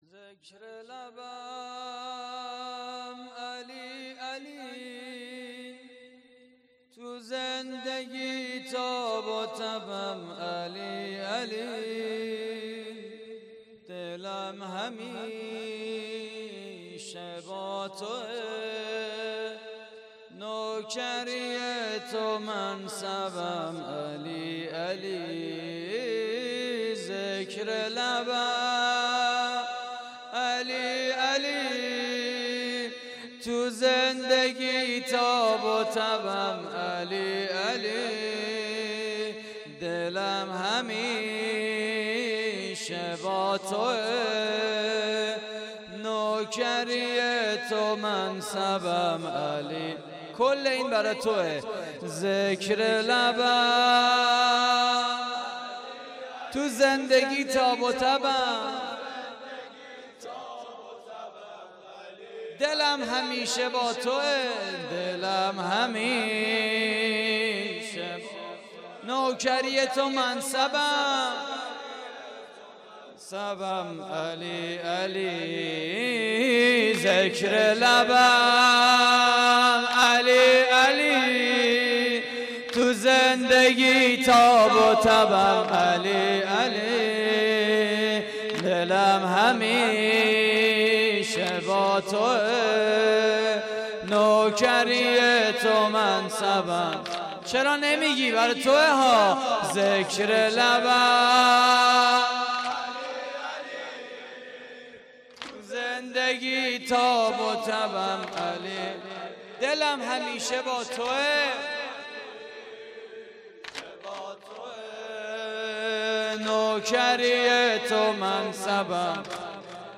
واحد: ذکر لبم علی علی
مراسم عزاداری شهادت حضرت امیر (ع) (22 رمضان)